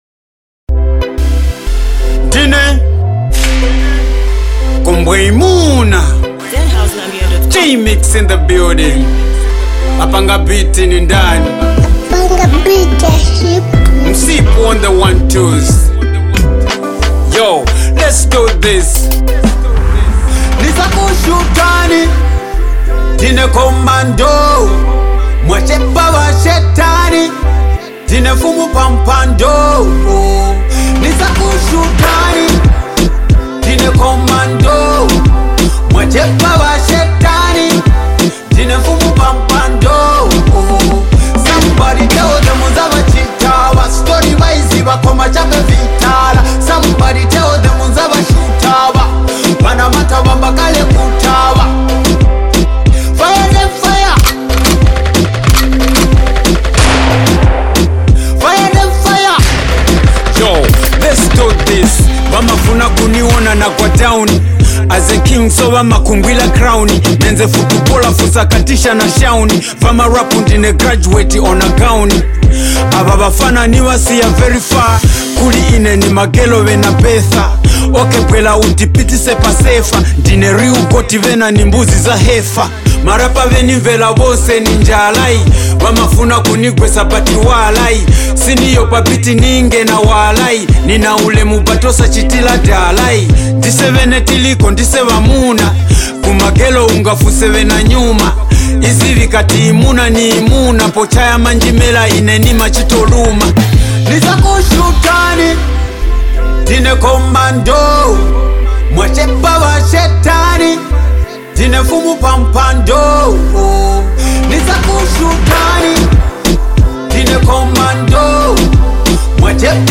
Every bar hits like a bullet, every verse a victory call.